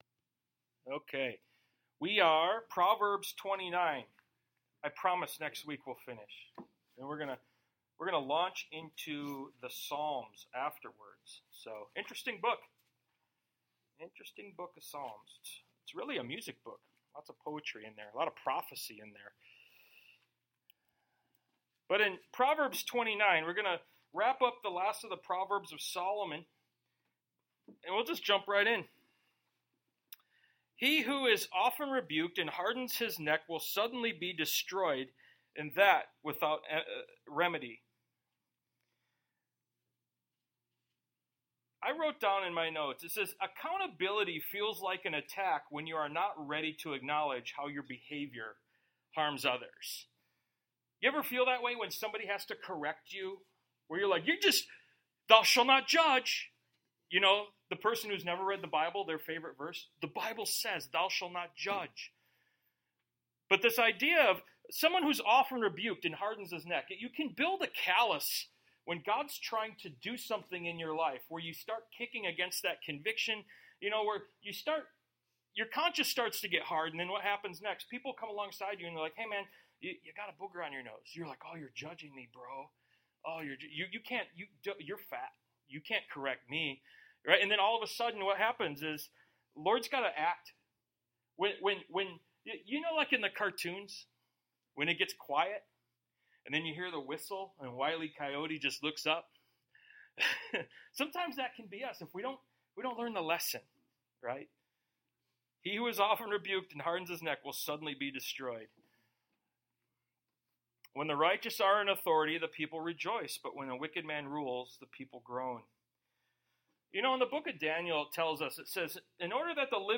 Service Type: Sunday Morning Bible Text: Do not add to His Word lest He rebuke you…